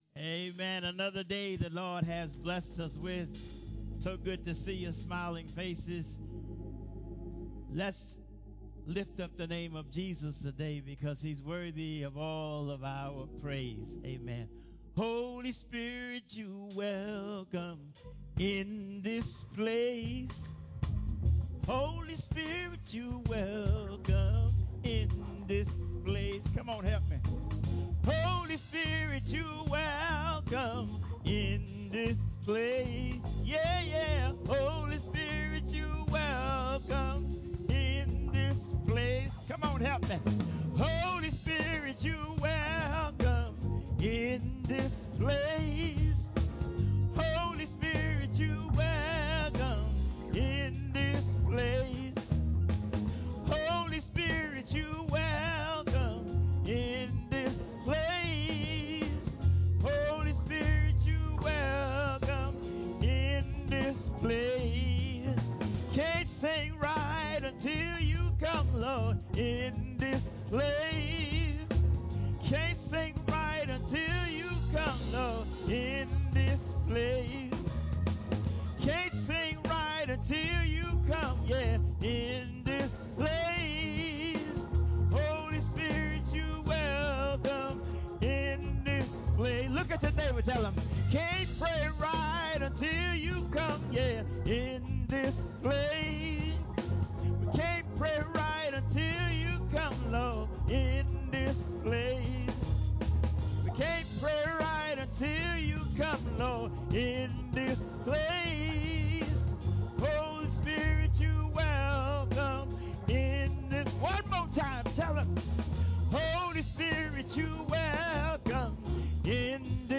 10:45 A.M. Service: Looking For Peace